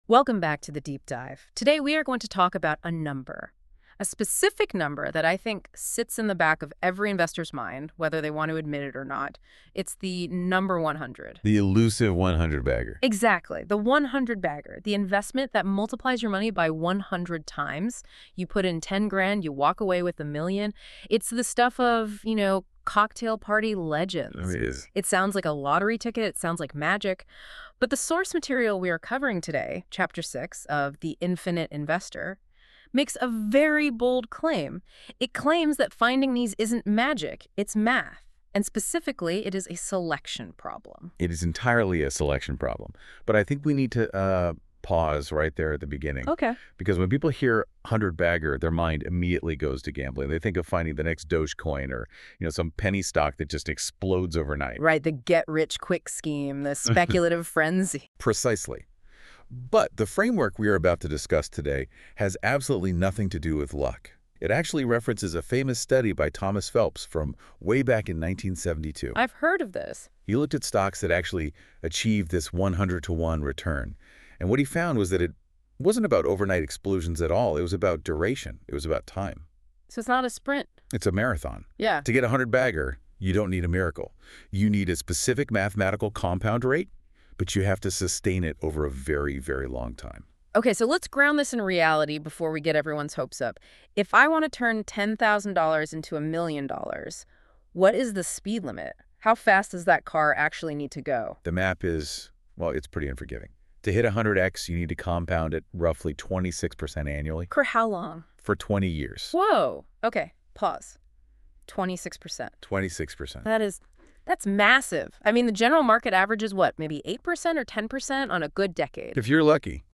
Listen to the deep-dive discussion – The Twin Engines of 100-Baggers (36:19 min)